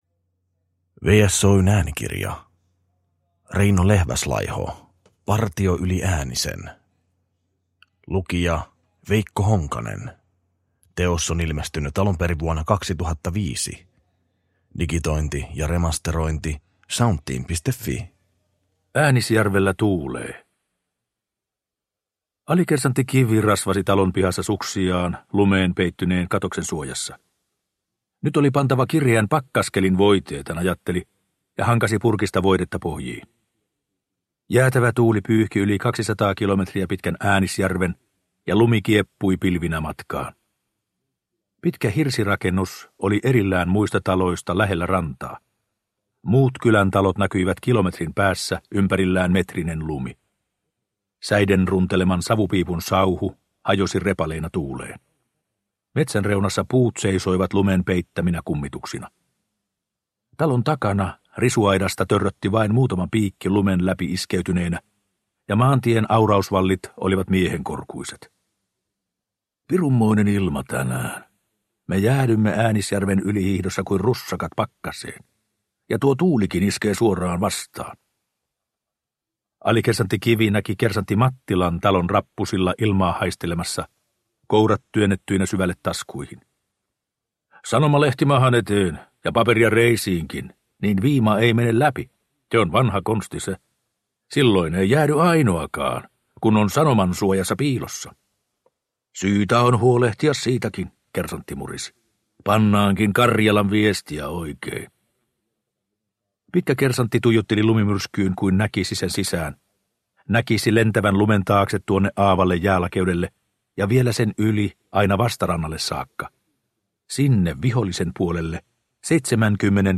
Partio yli Äänisen – Ljudbok – Laddas ner